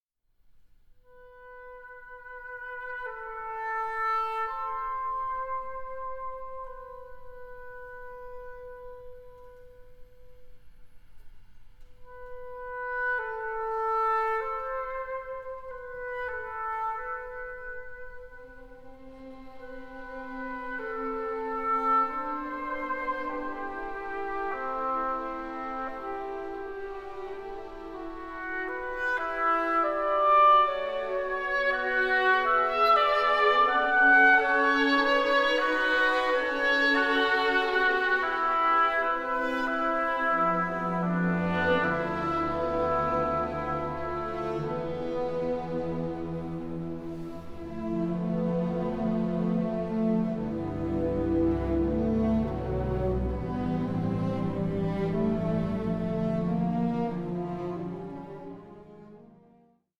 for Oboe and Strings